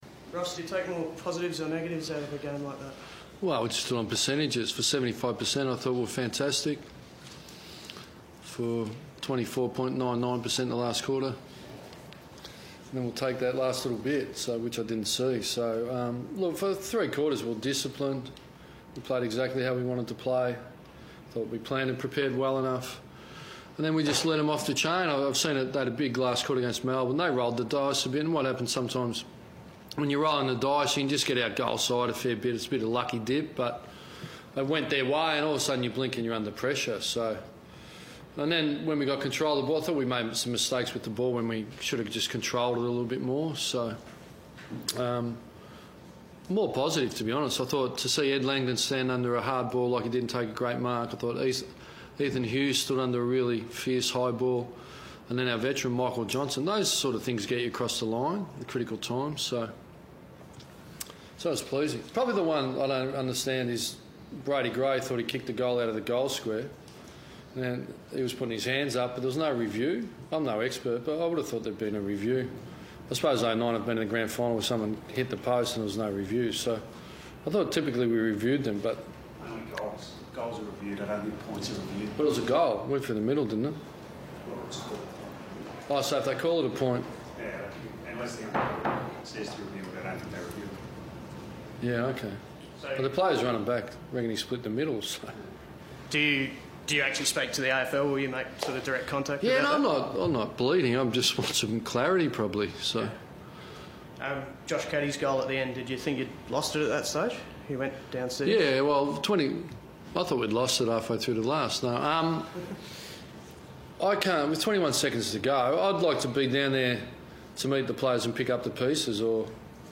Ross Lyon speaks to the media after Freo's nail biting win over the Tigers.